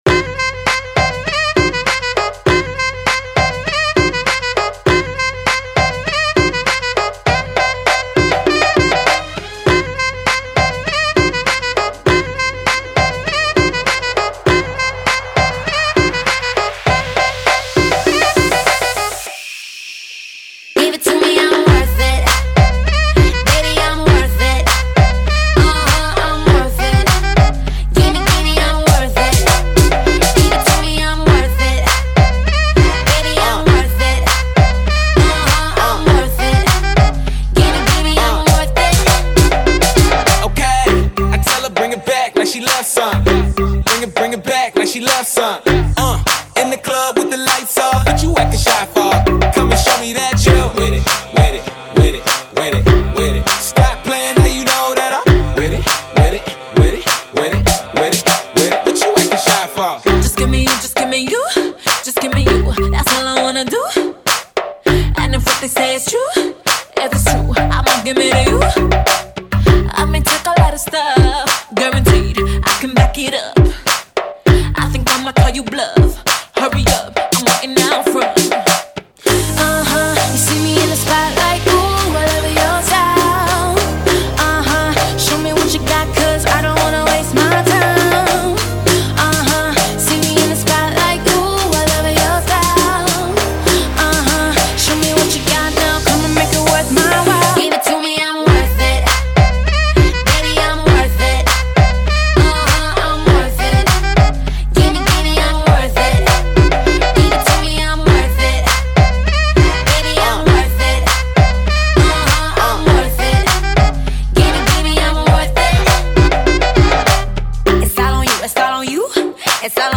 [ 100 Bpm ]